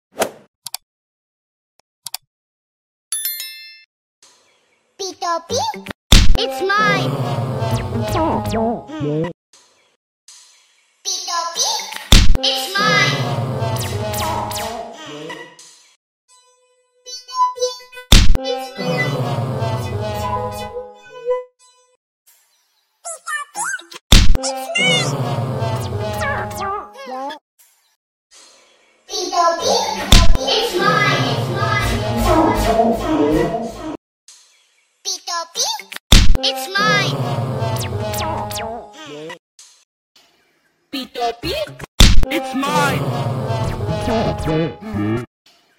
Mp3 Sound Effect Nina & Pocoyo "Give Me! It's Mine" & Om Nom "Crying" Sound Variations in 46 Seconds Nina & Pocoyo "Give Me!